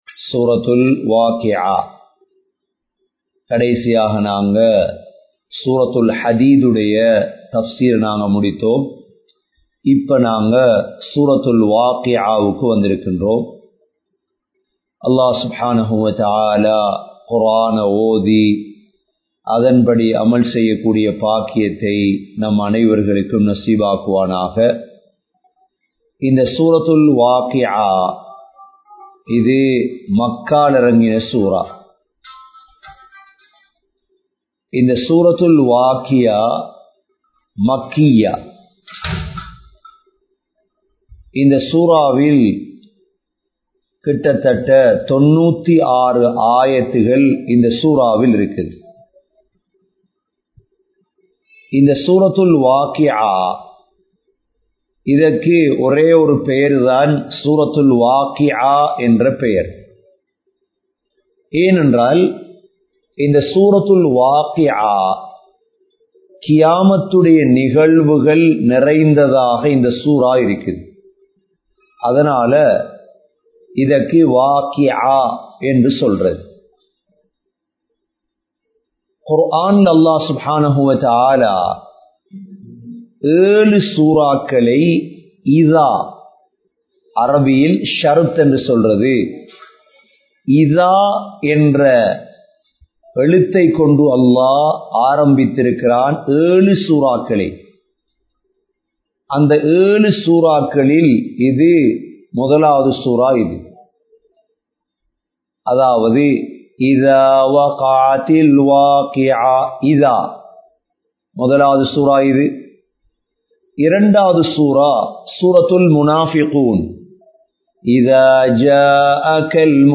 Grand Jumua Masjith